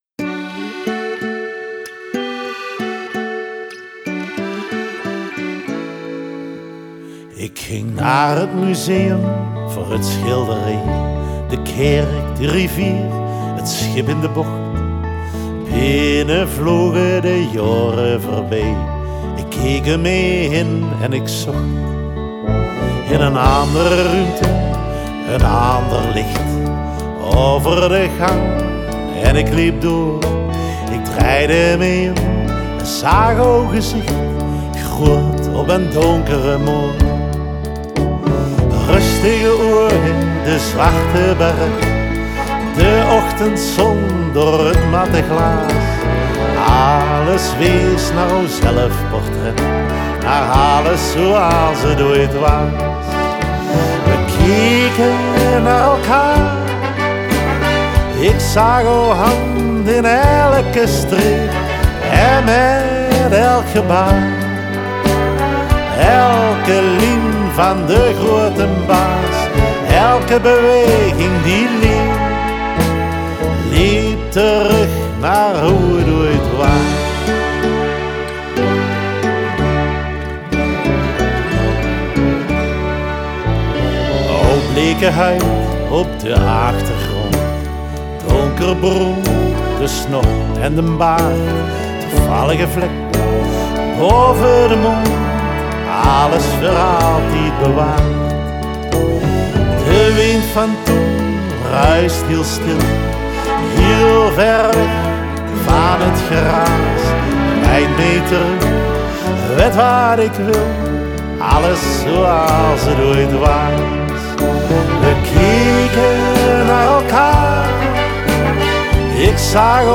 Они поют на северном нижне-франкском диалекте.
Genre: Ballad, folk